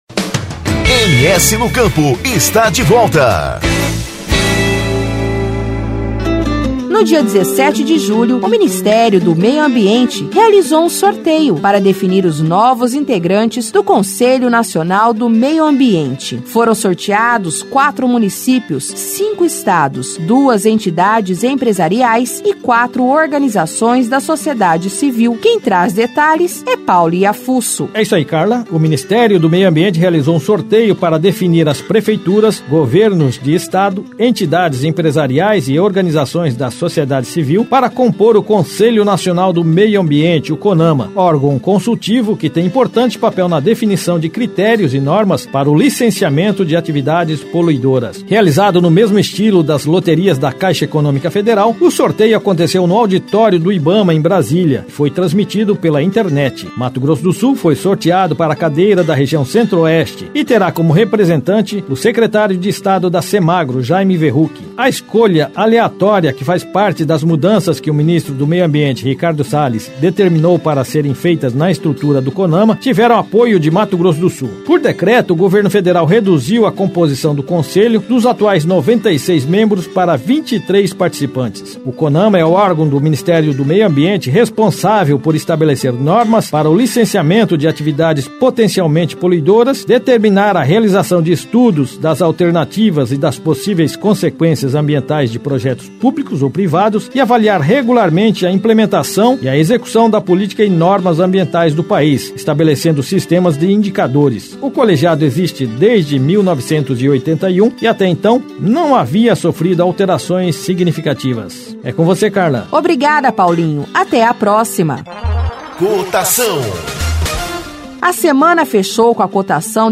Na Agenda Rural, informações sobre os principais eventos que movimentam o setor agropecuário no Estado e a agenda de cursos para o mês de julho do Serviço Nacional de Aprendizagem Rural (Senar-MS). Semanalmente distribuído para aproximadamente cem emissoras de rádio parceiras de frequência FM, com alcance nos 79 municípios, o MS no Campo ainda é disponibilizado para download no portal oficial do Governo de Mato Grosso do Sul, Portal MS .